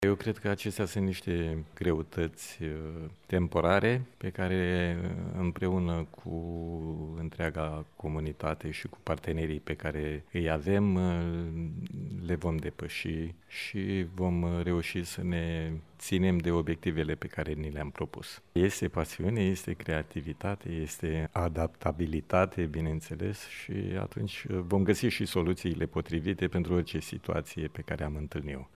Universitatea Națională de Arte „George Enescu” din Iași a deschis, astăzi, Anul Academic. Ceremonia a avut loc în Sala „Caudella”, aflată în Casa Balș, sediul Rectoratului UNAGE.